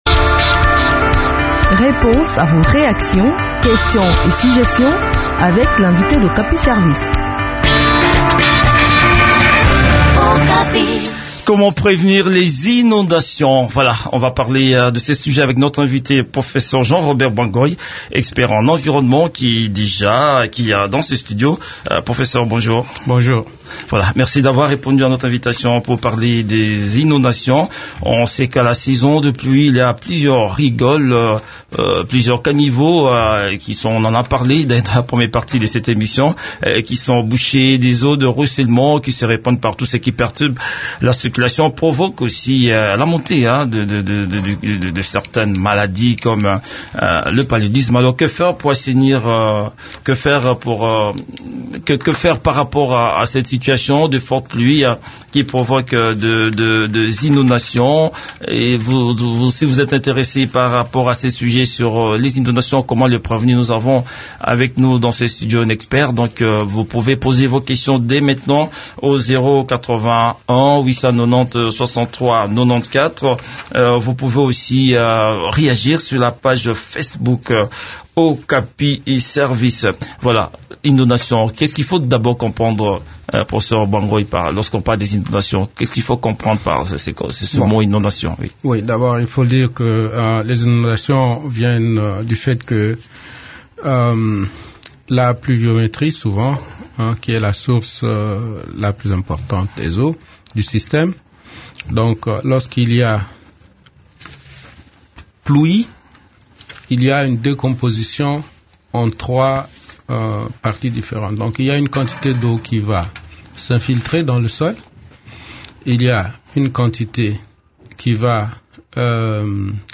expert en environnement